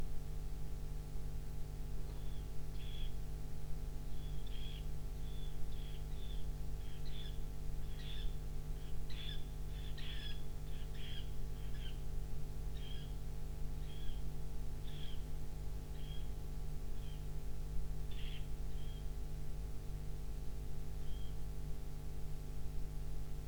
Charadrius dubius
Praias, ribeiras de ríos e lagos
Canto